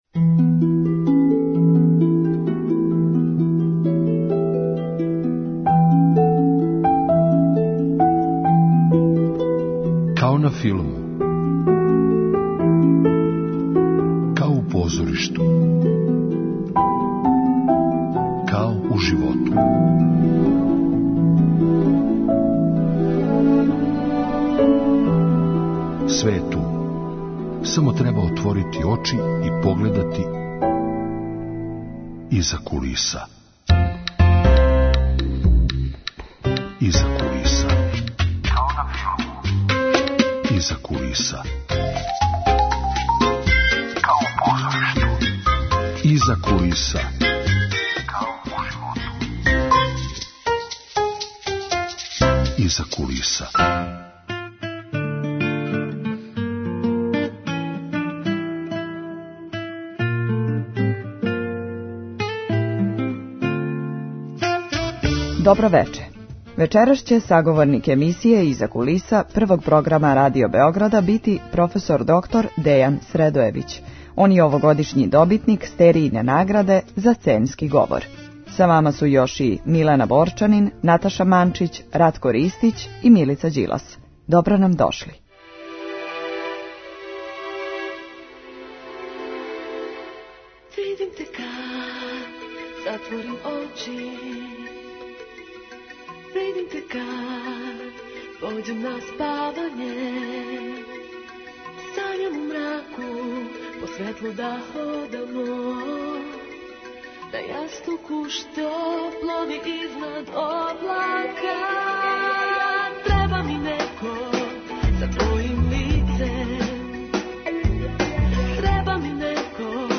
Емисија о филму и позоришту.